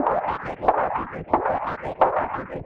RI_RhythNoise_90-04.wav